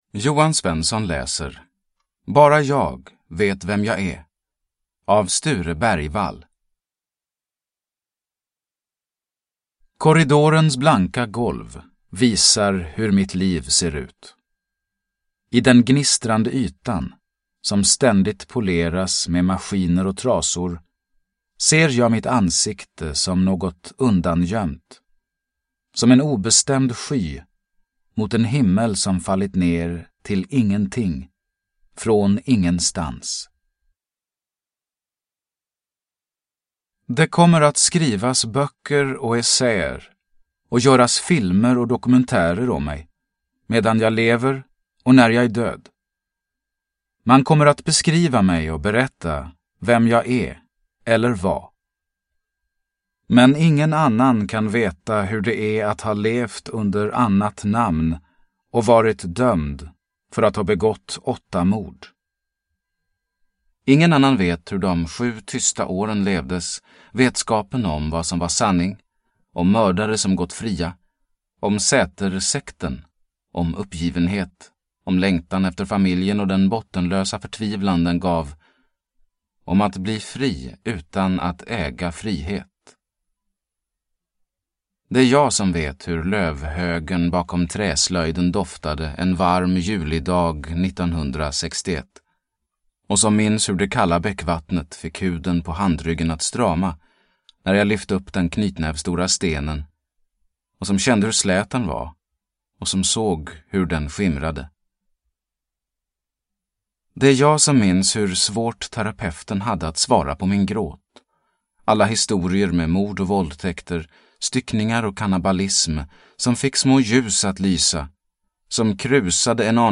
Bara jag vet vem jag är – Ljudbok – Laddas ner